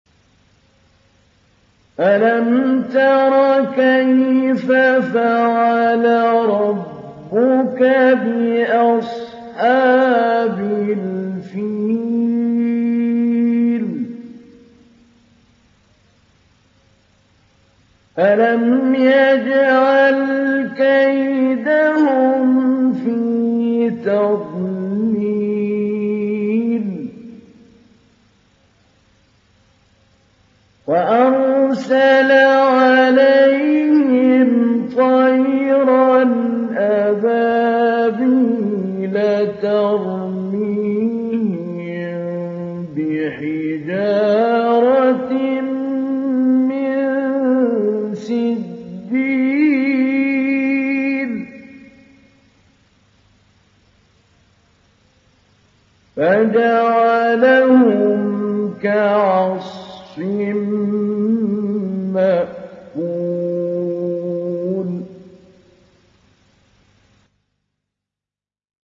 تحميل سورة الفيل mp3 بصوت محمود علي البنا مجود برواية حفص عن عاصم, تحميل استماع القرآن الكريم على الجوال mp3 كاملا بروابط مباشرة وسريعة
تحميل سورة الفيل محمود علي البنا مجود